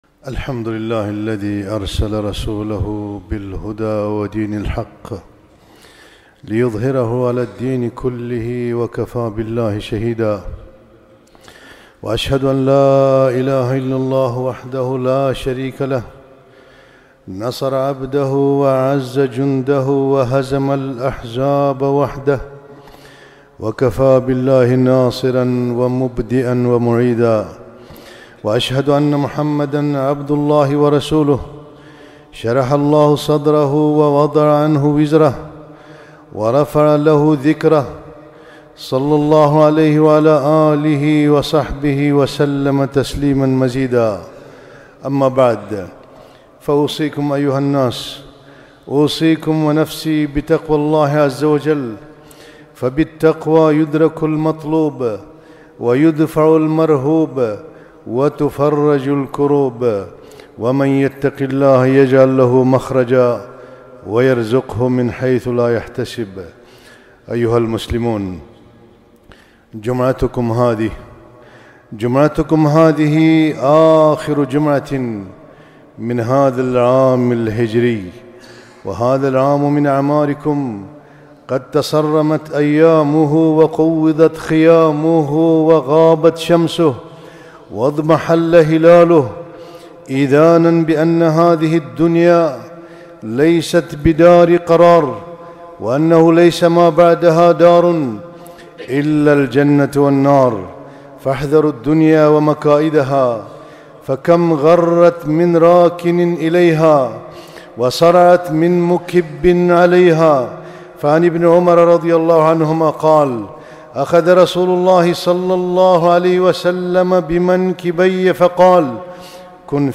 خطبة - تذكير بآخر العام، دروس عن الهجرة النبوية